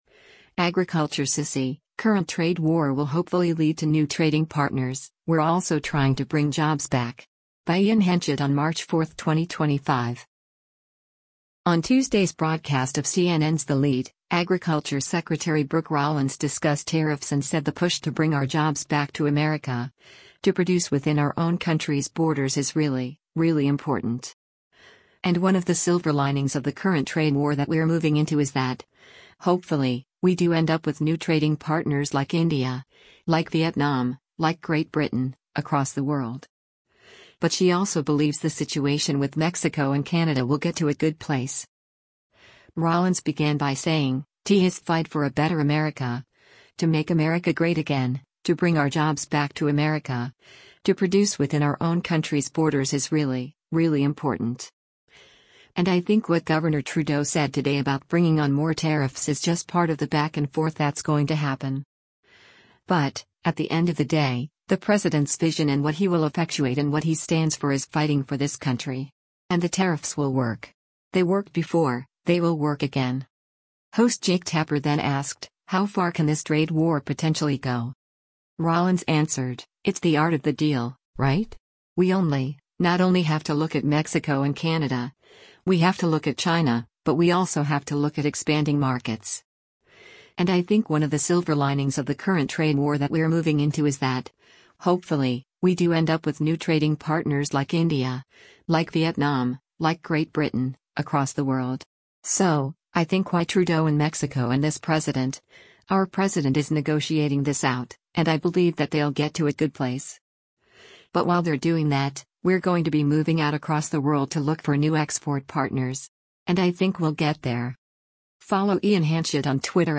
On Tuesday’s broadcast of CNN’s “The Lead,” Agriculture Secretary Brooke Rollins discussed tariffs and said the push “to bring our jobs back to America, to produce within our own country’s borders is really, really important.”
Host Jake Tapper then asked, “How far can this trade war potentially go?”